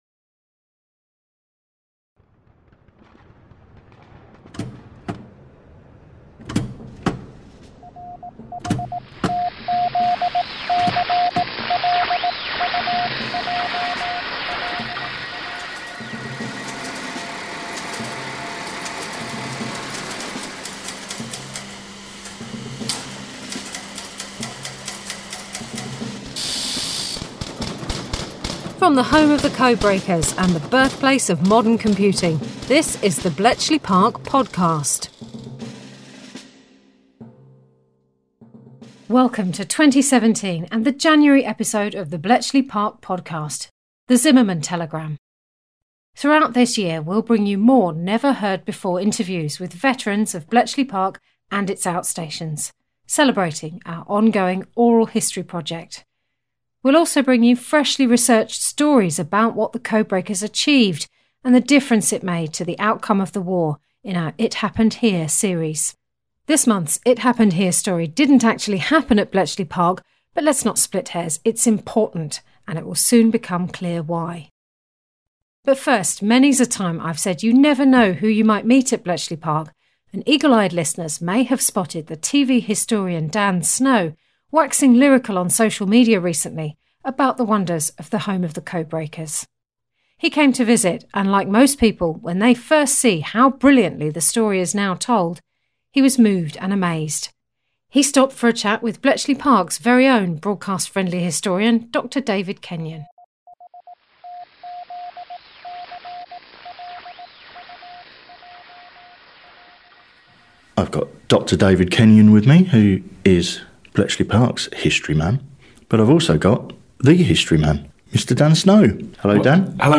Last summer their grandsons met up at Bletchley Park, reflecting on the significance of the telegram and their ancestors’ involvement in bringing it to light.